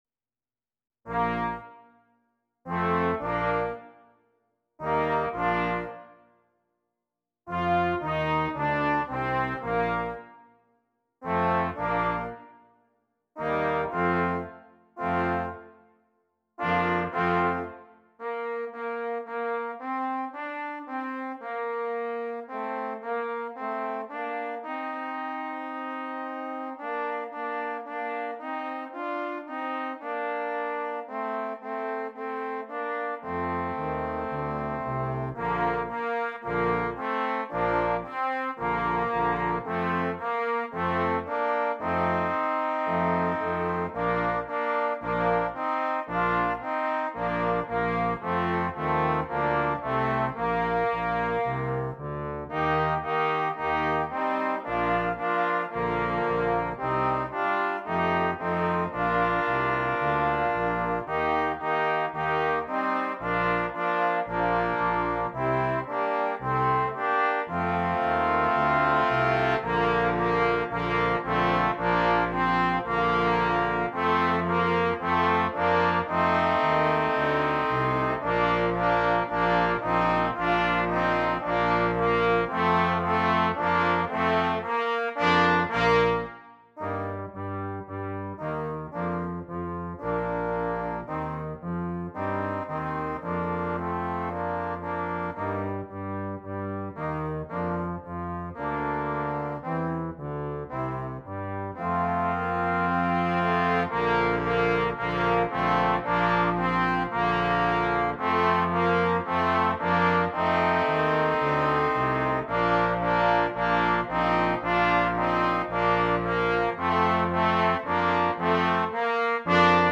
Brass Quintet
Difficulty: Easy Order Code